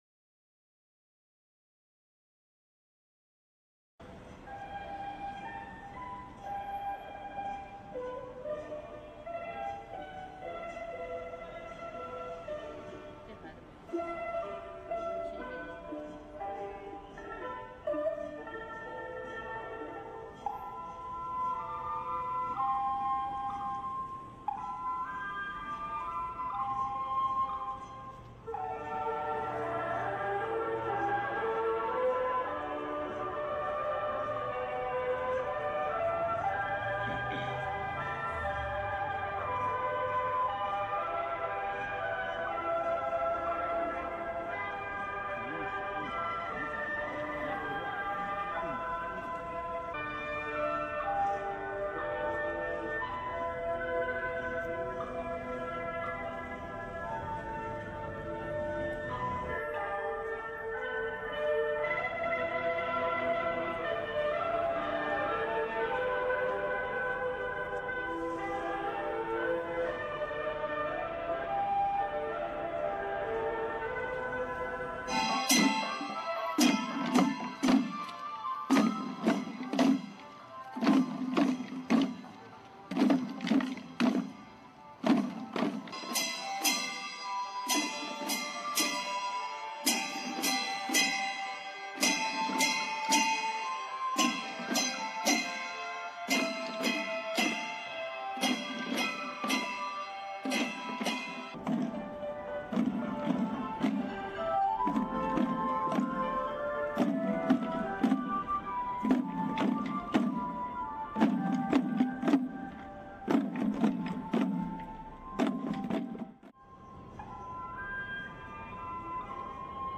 parade0509Tokyo.ra